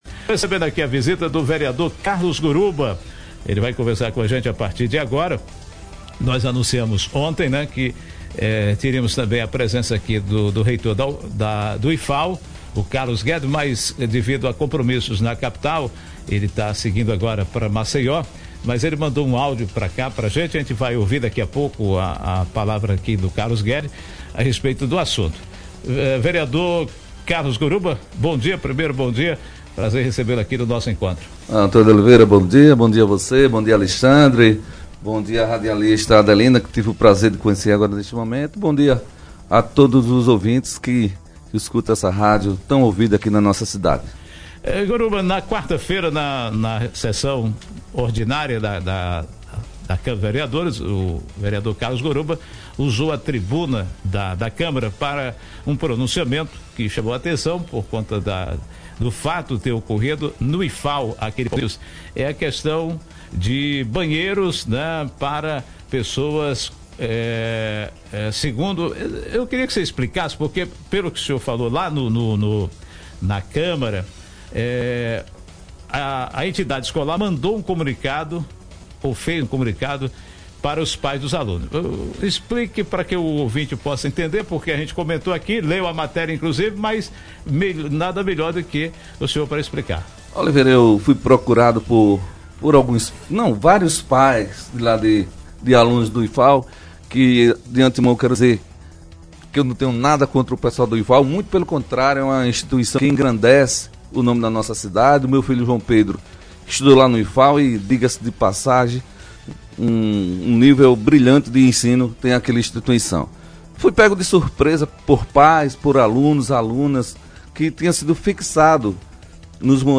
Carlos Guedes, reitor do Instituto Federal de Alagoas Carlos Guedes, reitor do Instituto Federal de Alagoas, participou do programa Nosso encontro por ligação e informou que a Resolução 17, que entrou em vigor em 2017,não é de autoria do campus Palmeira, e sim do Conselho Superior do IFAL (CONSUP).